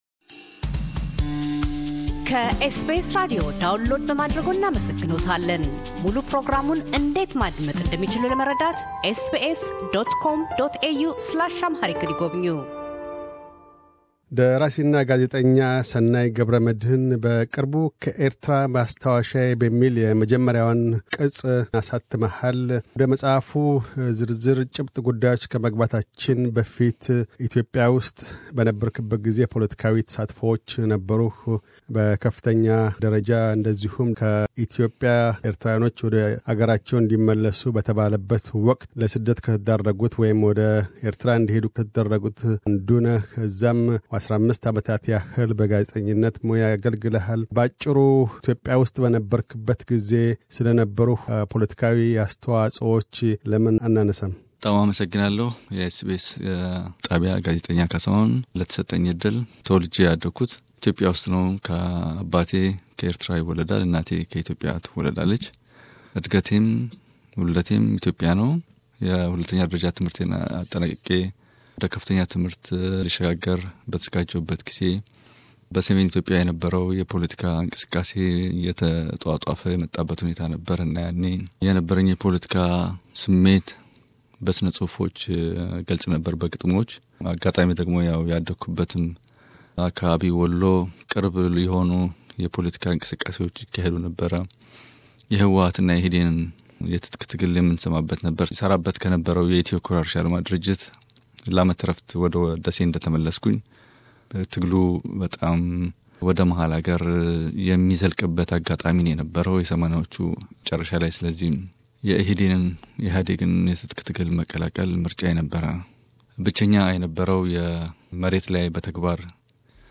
የተደረገ ቃለ መጠይቅ